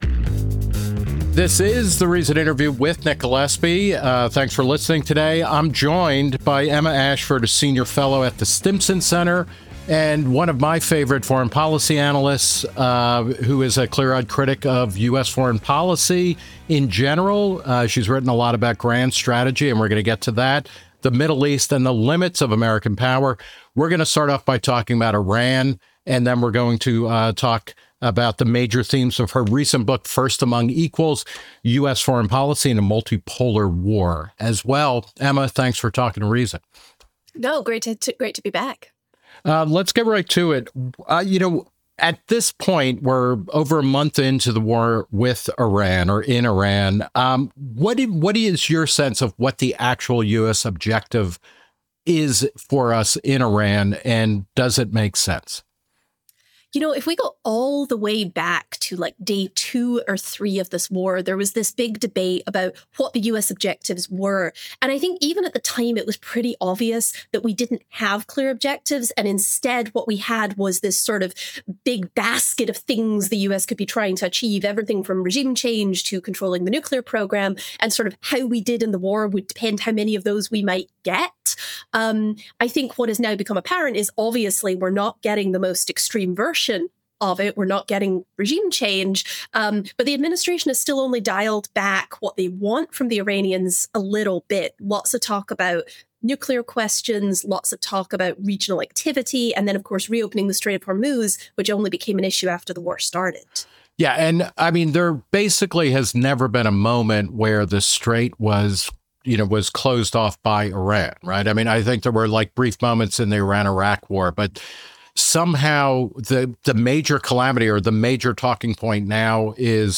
The Reason Interview